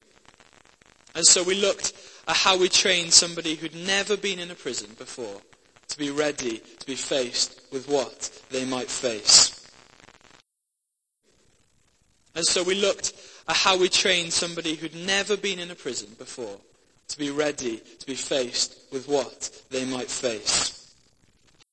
Hi I made a recording of a conference using USB stick in QU-Drive on our church A&H Qu-24 digital desk.
And in some sections during the session there appears very bad static type interference, like radio interference. For all of the sessions the speaker was using a Sennheiser handheld radio mic and there was none of this noise coming through the PA, everything sounded good.
It reduced the static a little bit but you can still hear it.